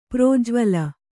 ♪ prōjvala